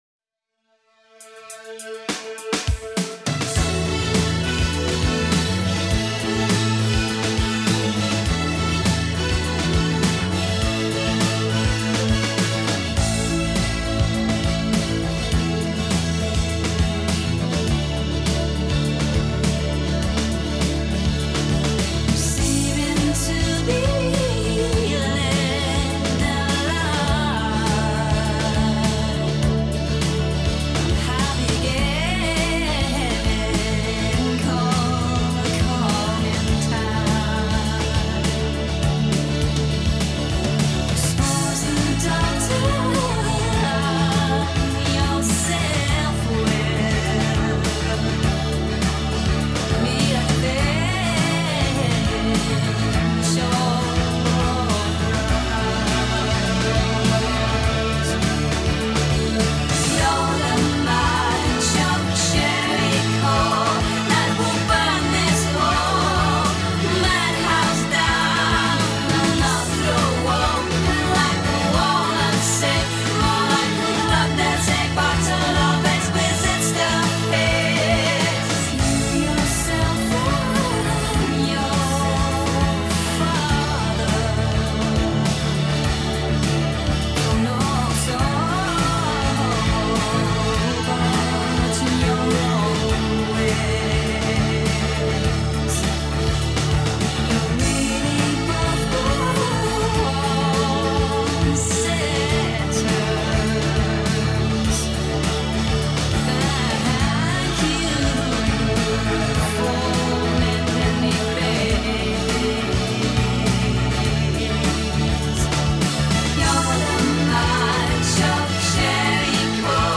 Dream pop
Дрим поп